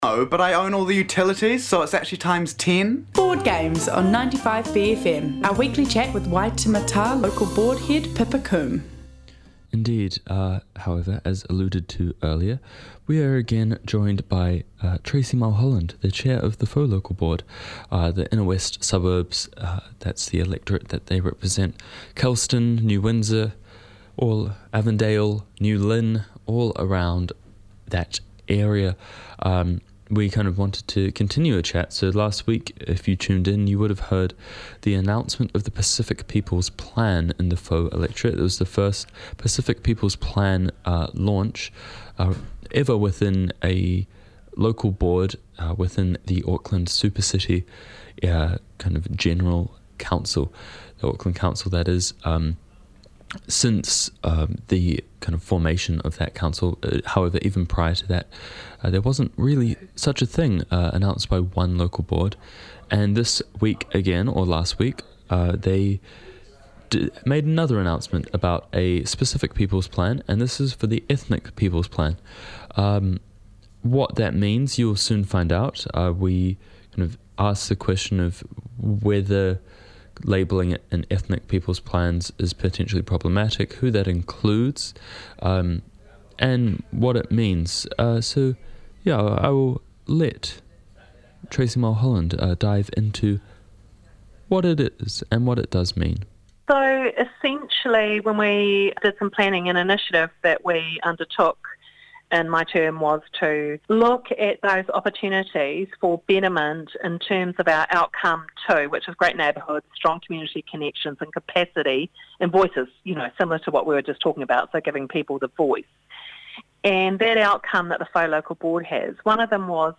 The Wire is 95bFM's long-running daily bastion of news, current affairs and views through the bFM lens.